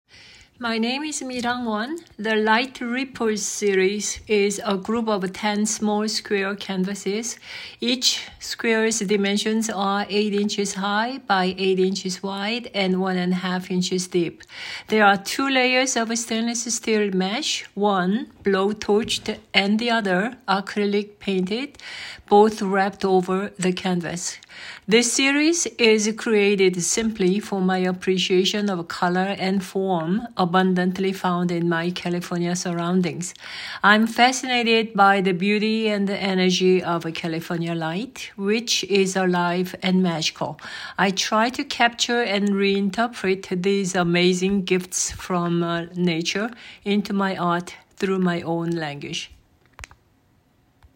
Audio Description for Light Ripples:
light-ripples.mp3